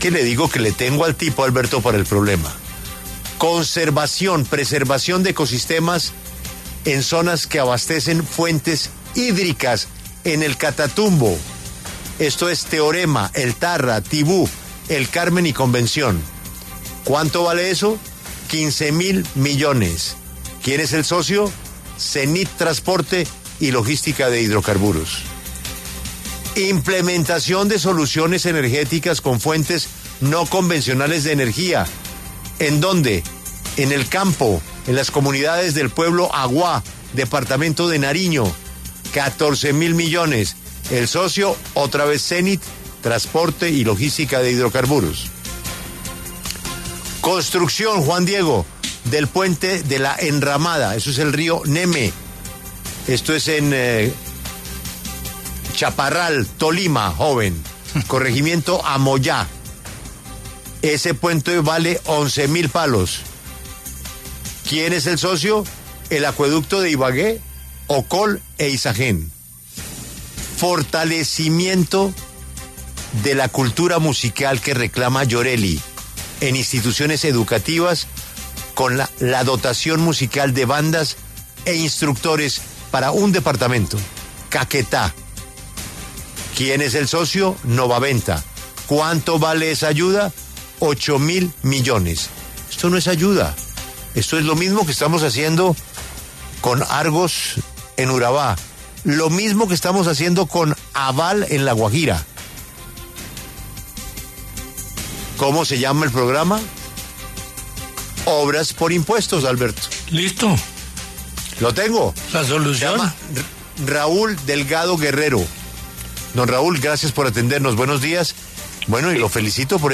Raúl Delgado, director de la Agencia para la Renovación del Territorio, explicó en La W qué es Obras por Impuestos.